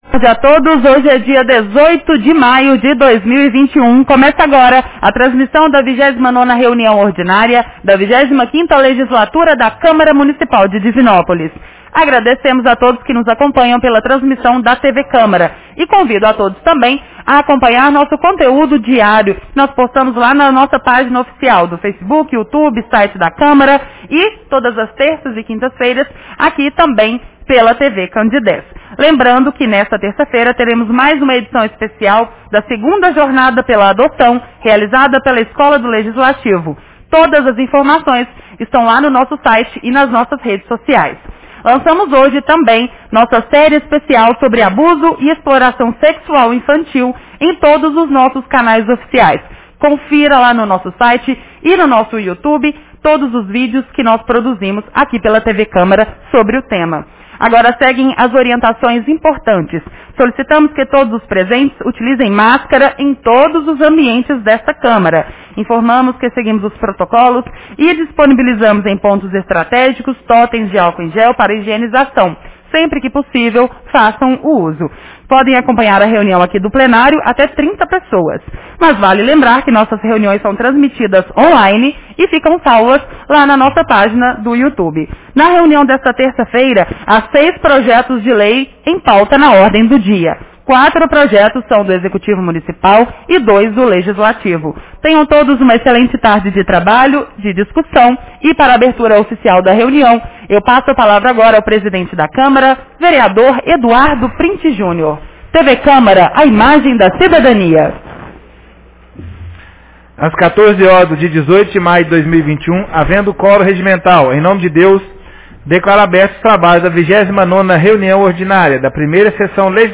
Reunião Ordinária 29 de 18 de maio 2021